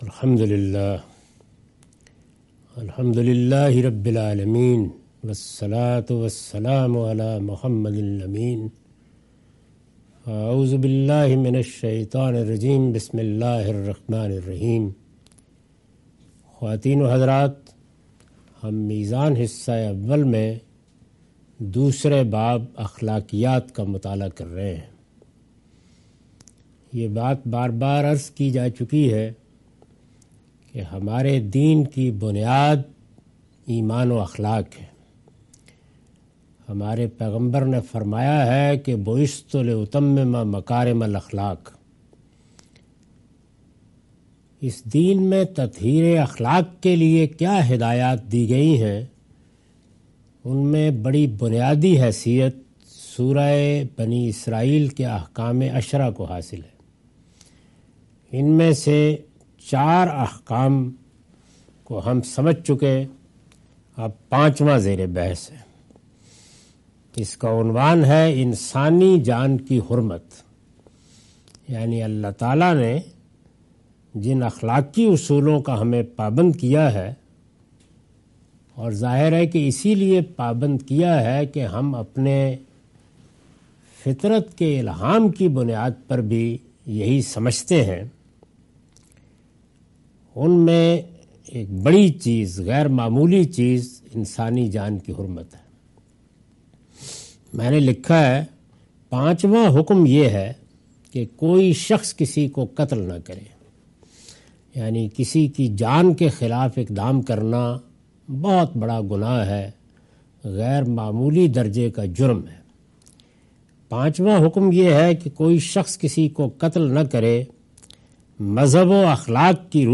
Meezan Class by Javed Ahmad Ghamidi.